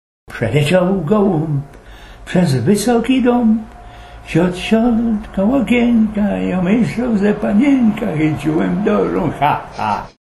Klarnecista
(region rzeszowski)